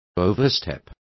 Complete with pronunciation of the translation of oversteps.